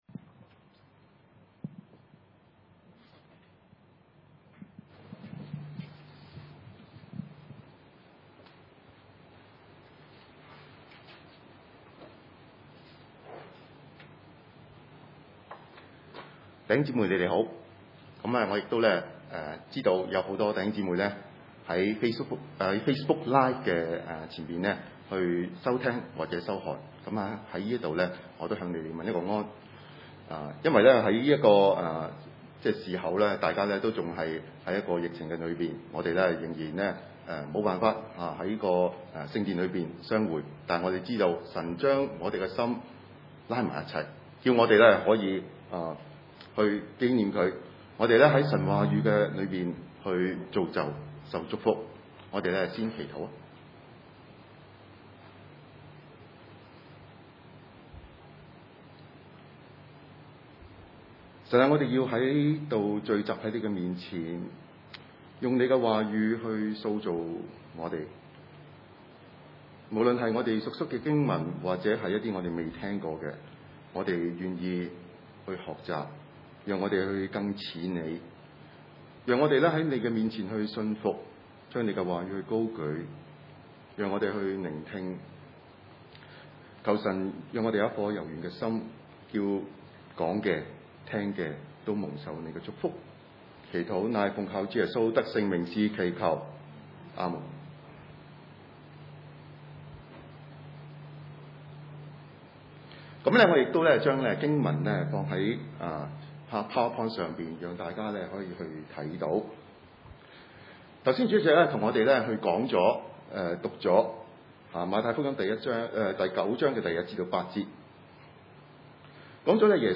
太 九1-8 崇拜類別: 主日午堂崇拜 經文：馬太福音第9章1-8節（聖經‧新約） 1.